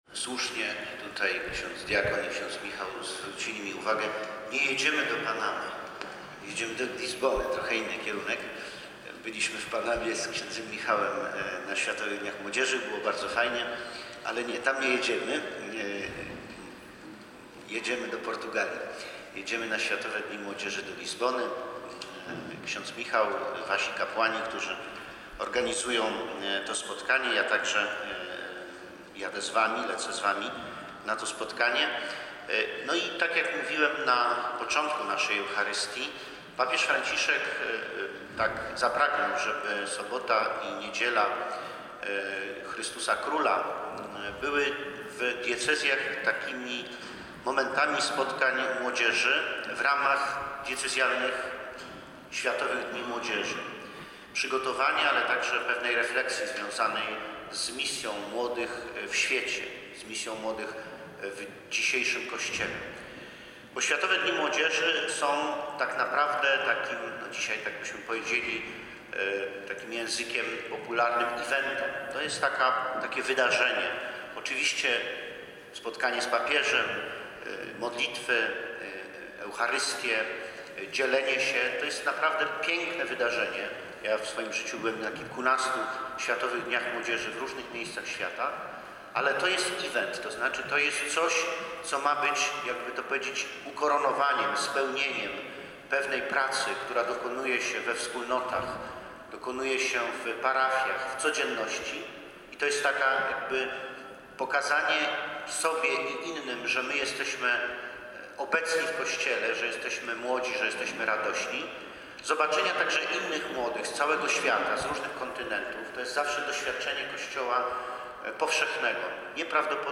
Biskup pomocniczy diecezji warszawsko-praskiej Jacek Grzybowski uczestniczył w obchodach Światowych Dni Młodzieży na poziomie diecezjalnym. Przewodniczył uroczystej Mszy Świętej w parafii Najświętszej Maryi Panny Matki Kościoła w Sulejówku.